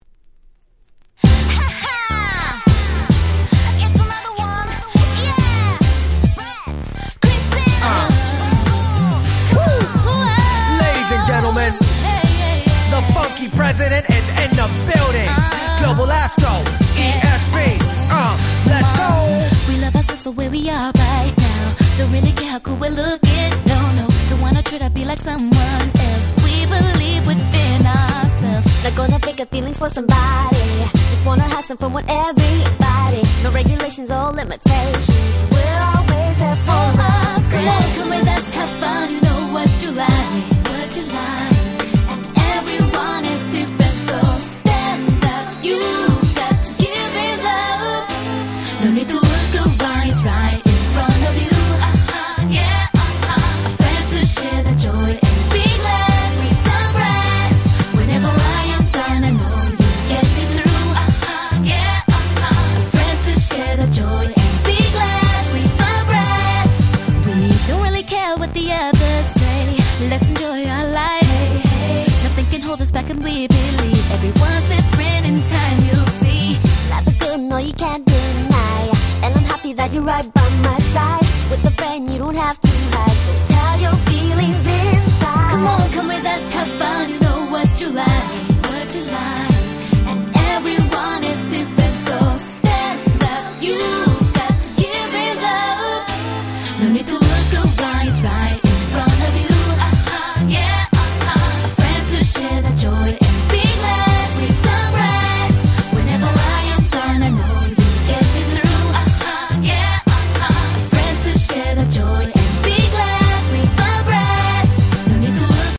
03' Very Nice R&B !!